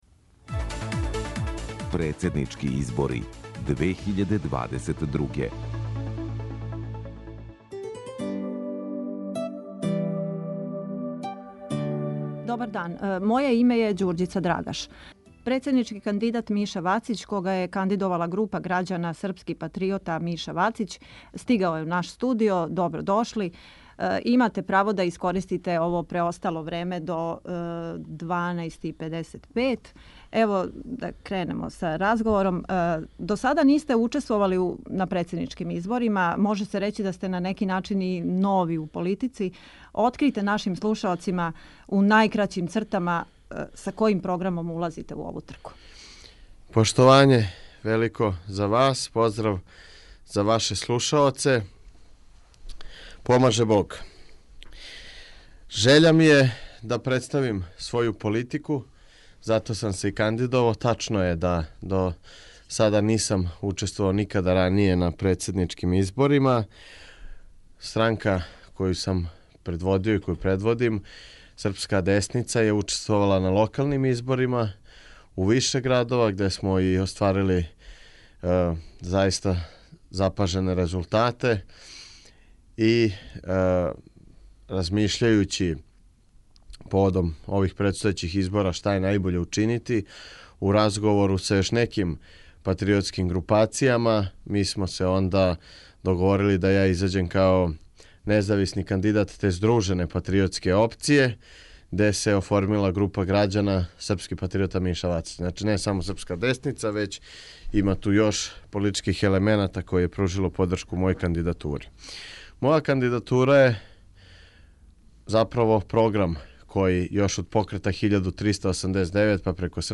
Представљања председничких кандидата